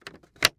Case Plastic Lock Sound
household